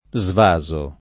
svasare v.; svaso [ @ v #@ o ]